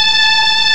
STRINGS  4.1.wav